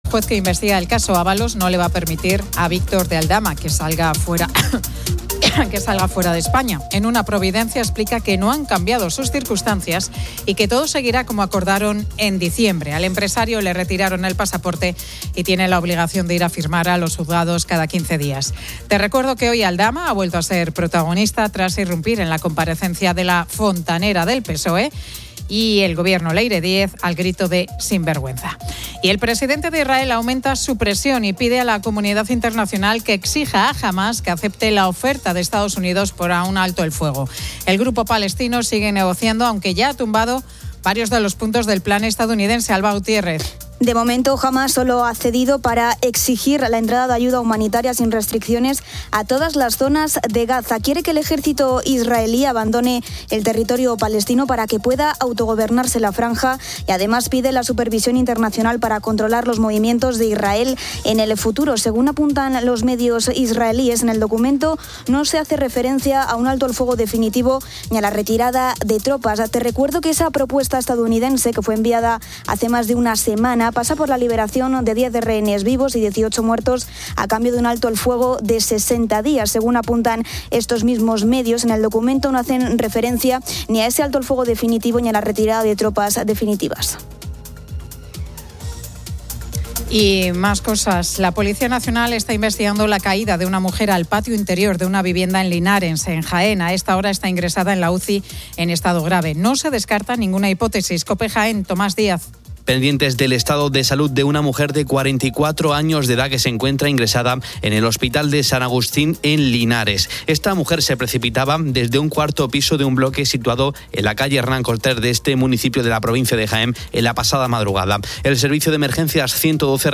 También entrevista a Fernando López Miras, Presidente de la Región de Murcia.